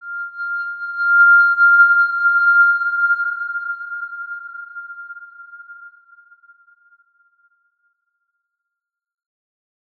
X_Windwistle-F5-mf.wav